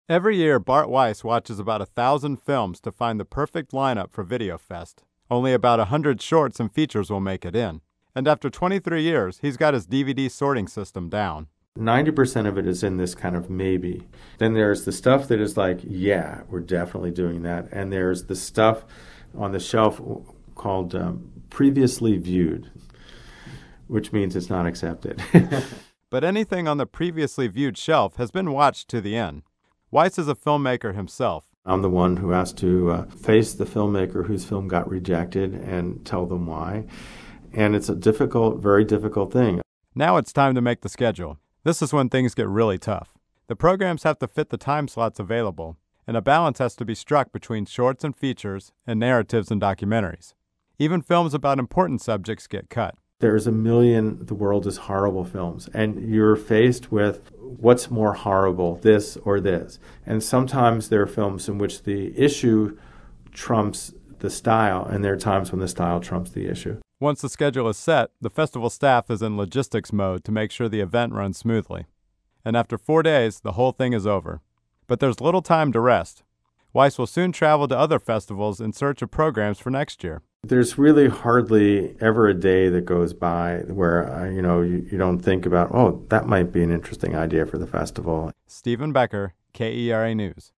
• KERA radio story: